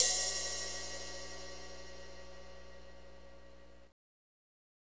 Index of /90_sSampleCDs/Northstar - Drumscapes Roland/DRM_Slow Shuffle/CYM_S_S Cymbalsx